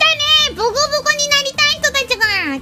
Worms speechbanks